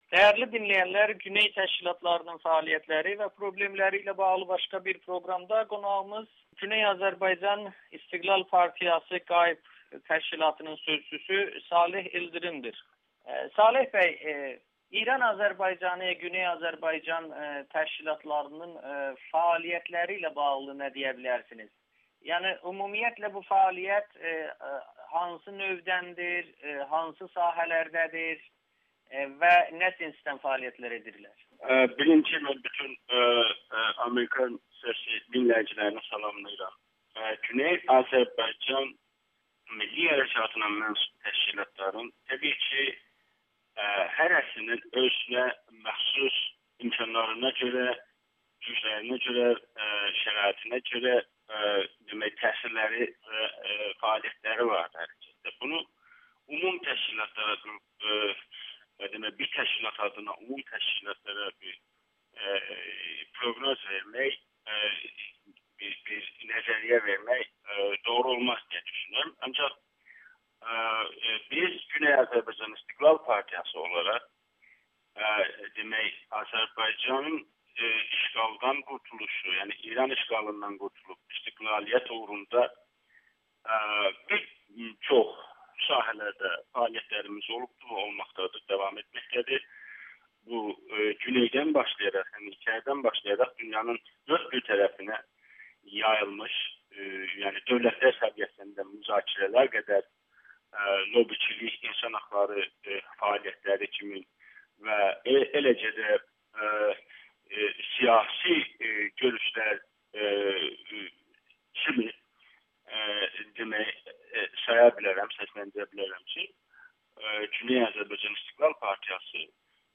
Təşkilatlarımızın birinci problemi maddi sıxıntıdır [Audio-Müsahibə]